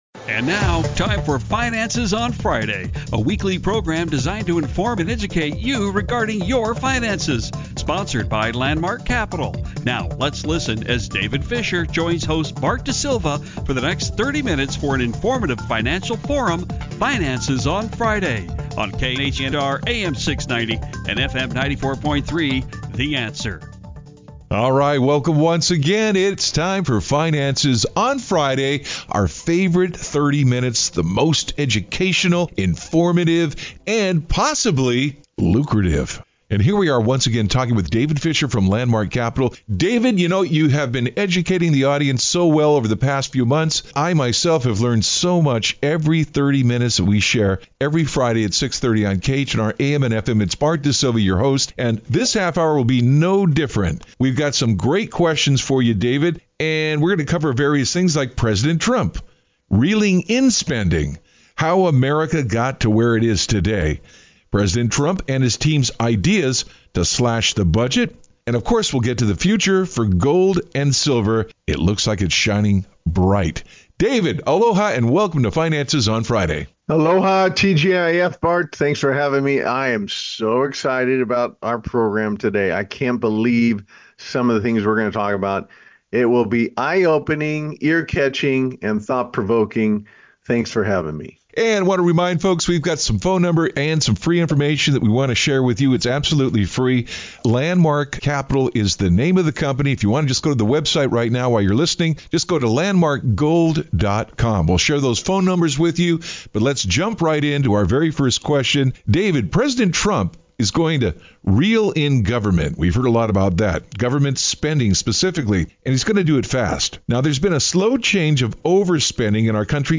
radio talk show